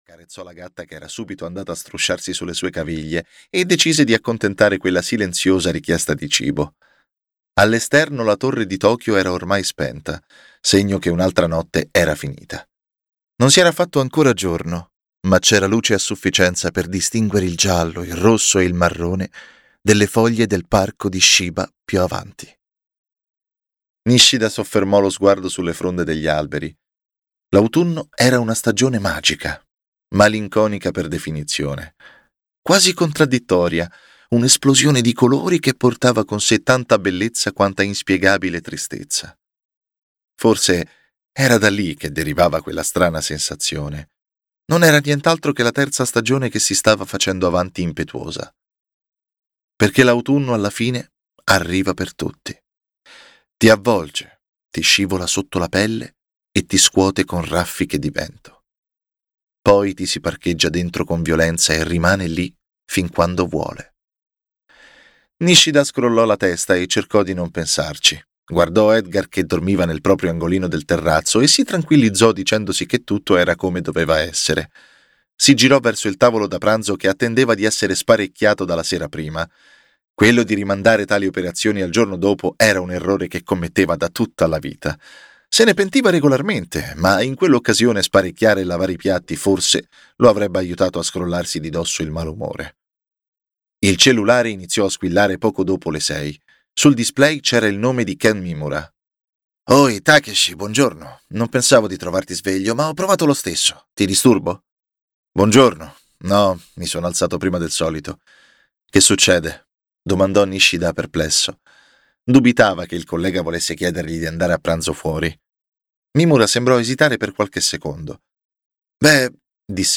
"I diavoli di Tokyo Ovest" di Tommaso Scotti - Audiolibro digitale - AUDIOLIBRI LIQUIDI - Il Libraio